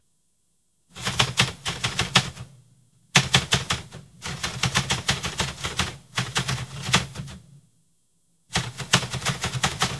Еще одна печатная машинка